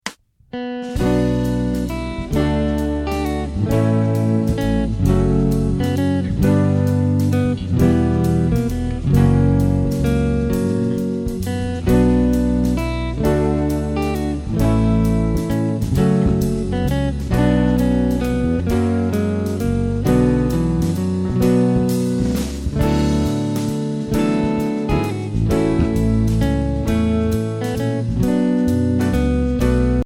Pop/Guitar/Folk Guitar/Bass Instruction